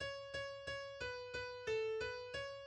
key Bm